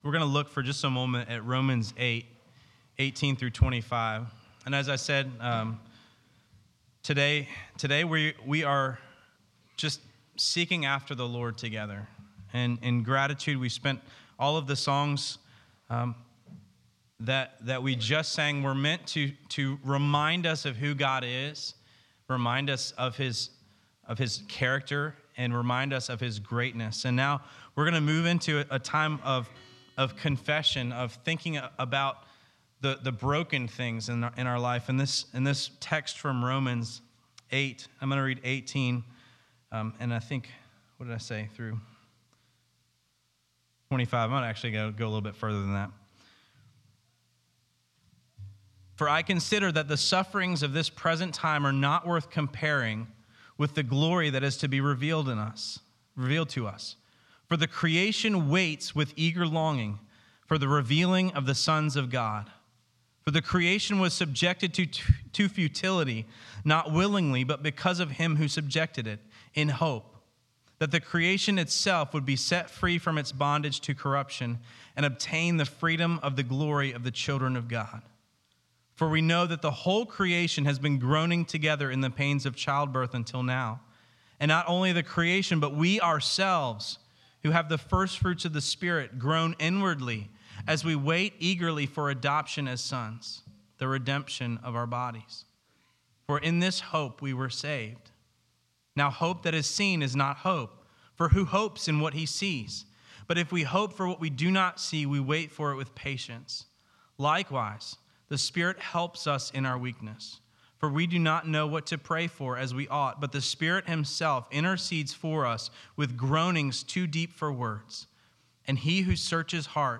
This week we did a lot more singing and prayer, and I shared a short meditation out of Romans.